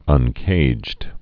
(ŭn-kājd)